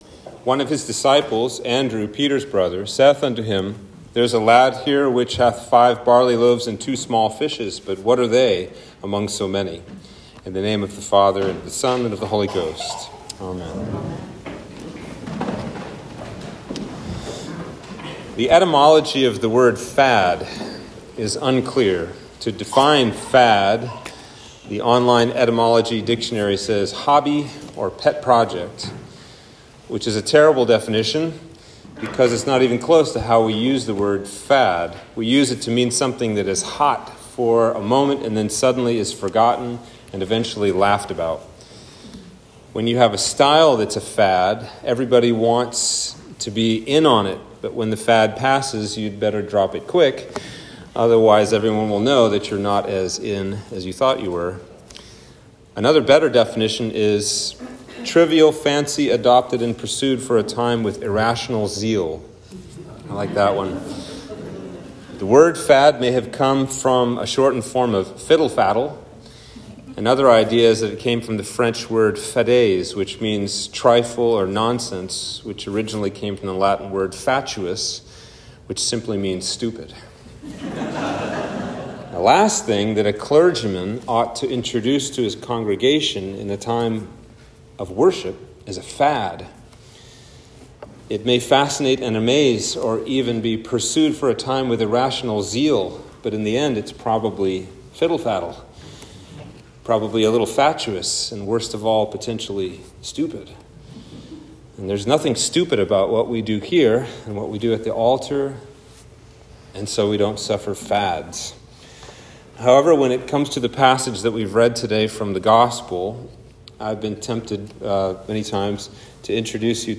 Sermon for The Sunday Next Before Advent